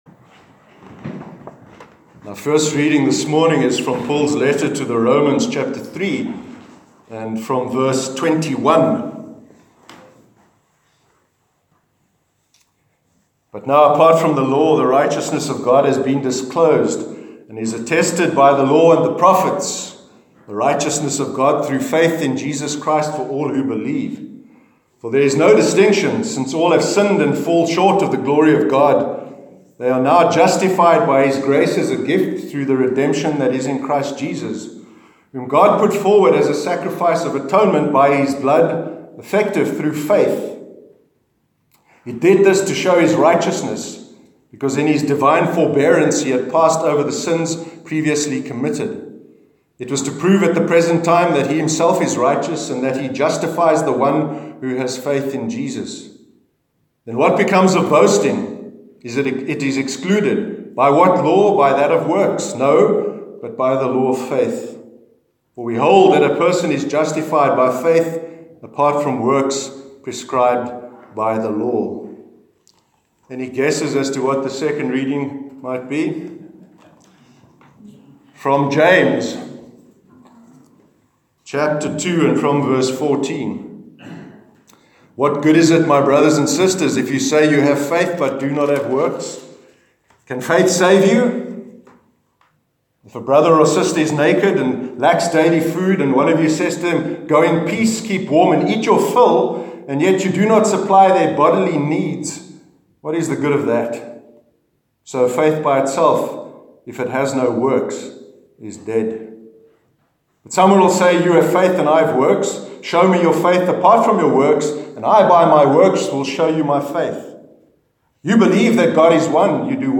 Sermon on Faith and Works- 6th May 2018
sermon-6th-may-2018.mp3